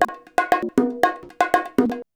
119BONG09.wav